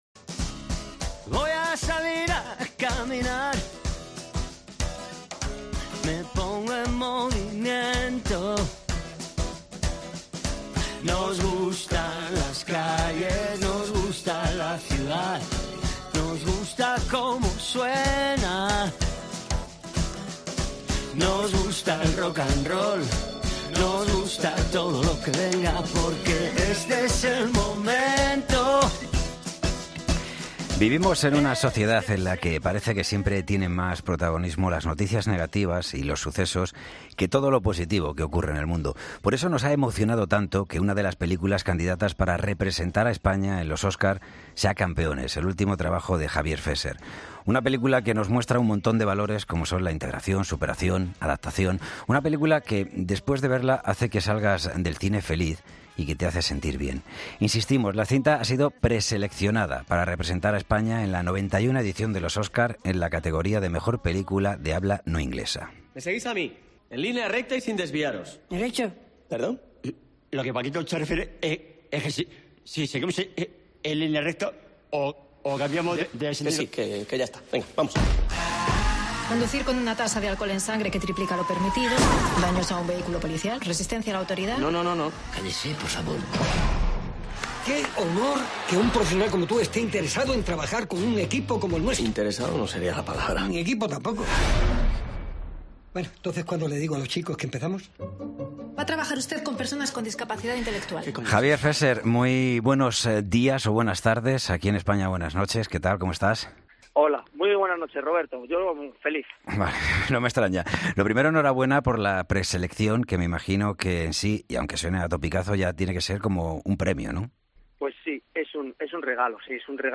Entrevista a Javier Fesser, director de 'Campeones'